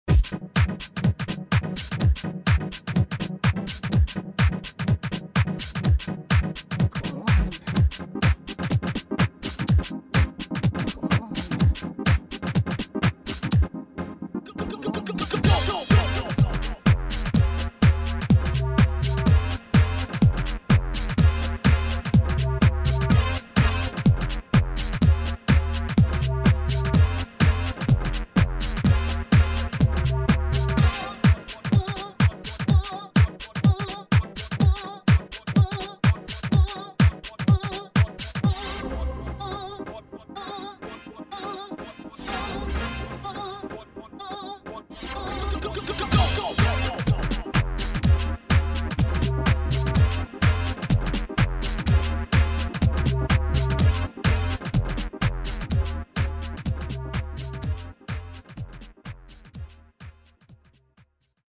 Budget rompler synthesizer dance/techno oriented with some real-time options.
house drum kit *